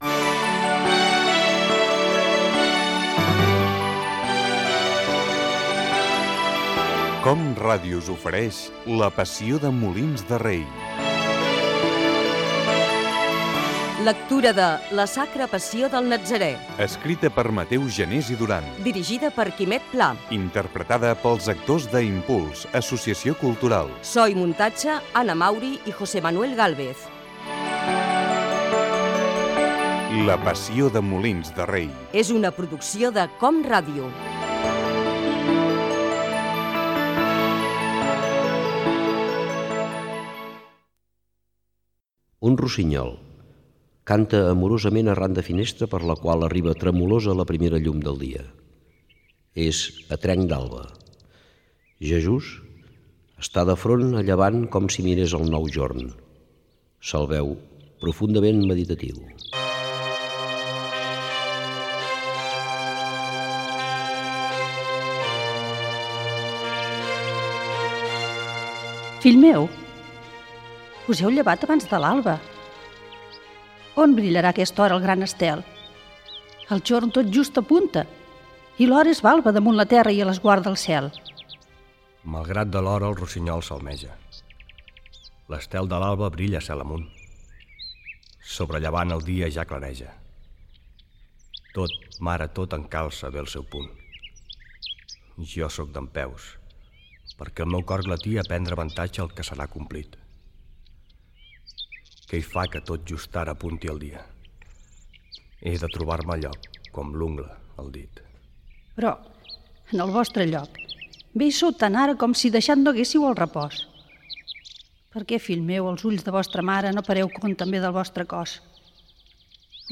Lectura interpretada de "La sacra Passió del Natzaré" de Mateu Janés i Durán. Careta del programa i primeres escenes.
Ficció
Actors de l'Associació Cultural Impuls